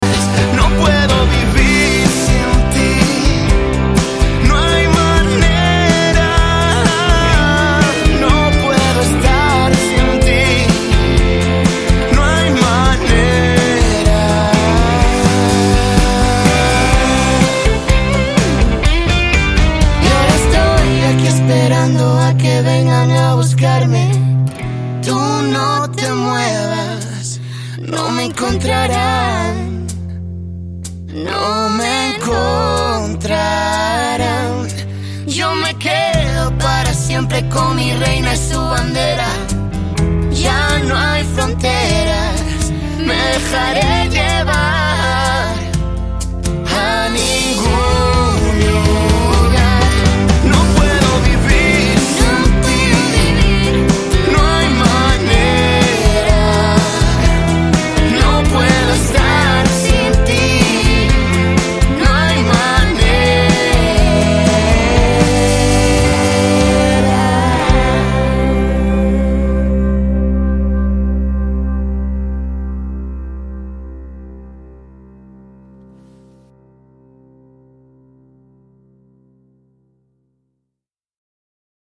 поп
красивые
дуэт
мужской и женский вокал